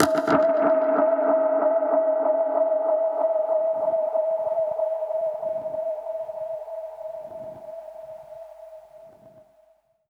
Index of /musicradar/dub-percussion-samples/95bpm
DPFX_PercHit_A_95-09.wav